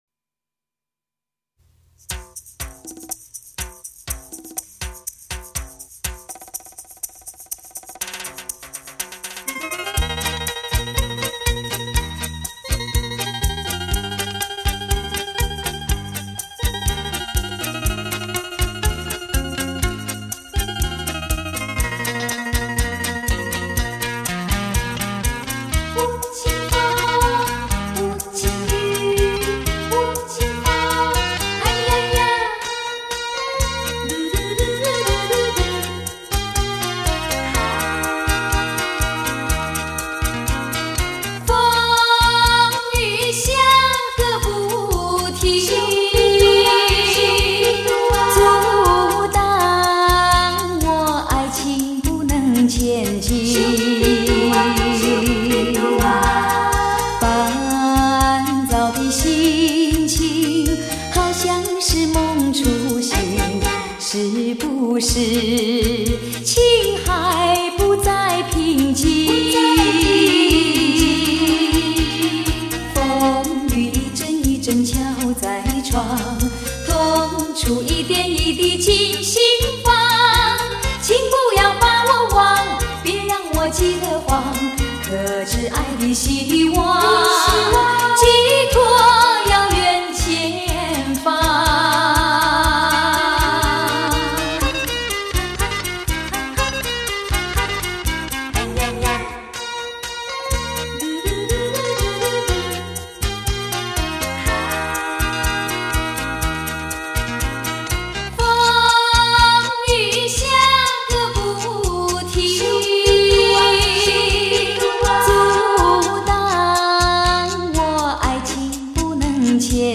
录音：台北乐韵录音室 广州国光录音棚